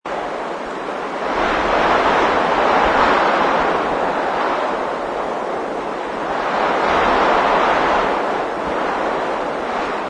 ambience_weather_wind.wav